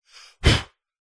Звуки человека
Мужской короткий выдох дыхание 3